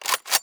ShotgunLoad3.wav